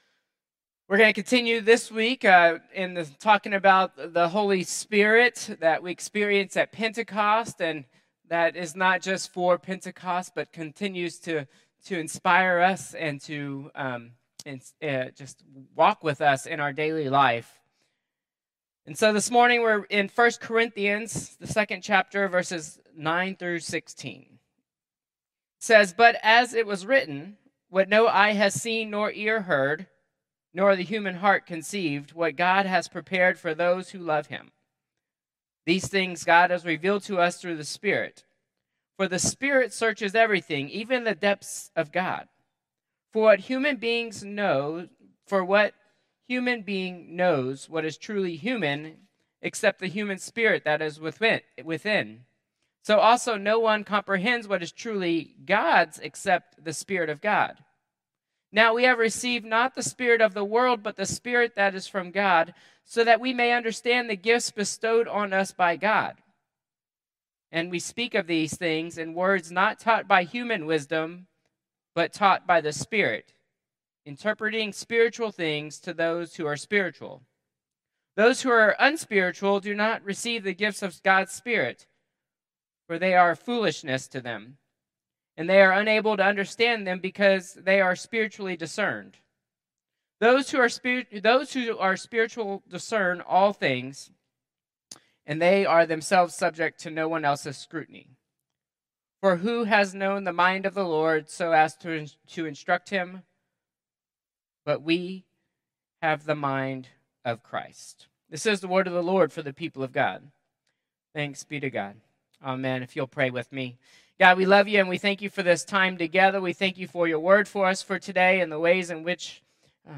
Contemporary Service 6/22/2025